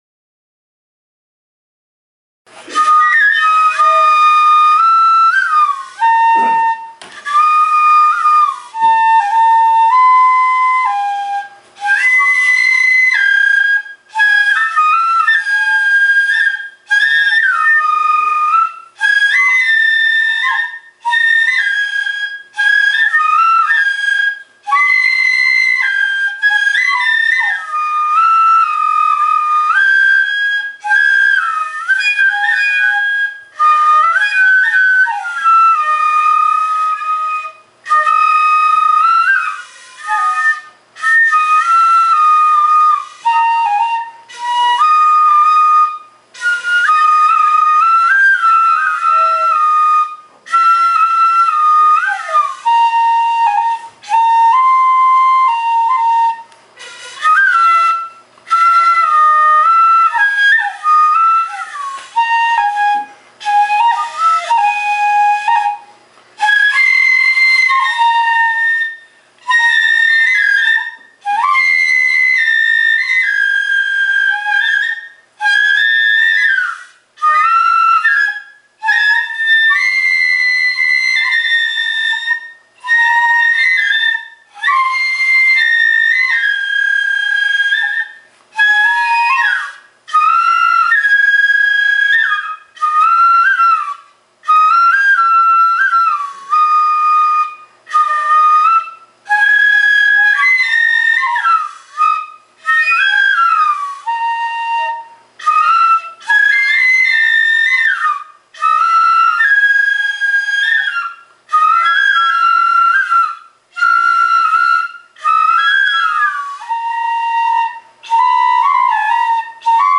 笛の音　　一式（音声）